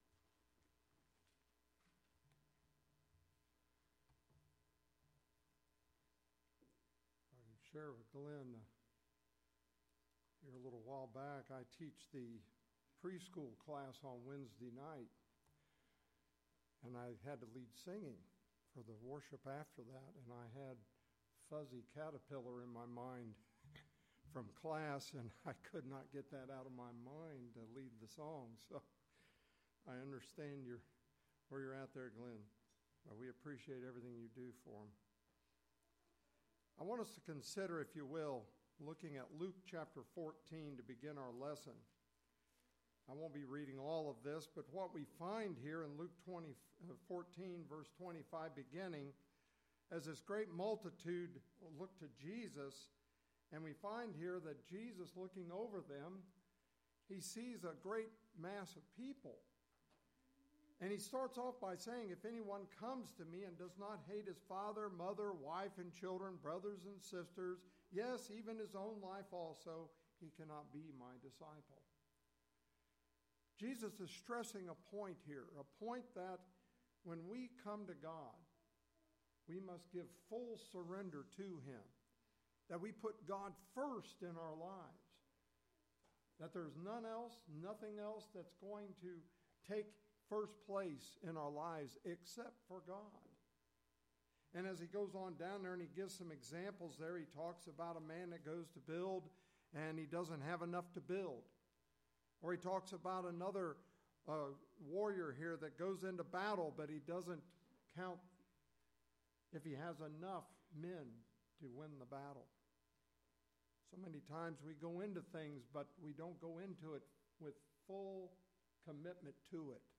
The sermon’s central message is that following Christ requires “full surrender” or being “all in” for God—placing Him above family, possessions, comfort, and self.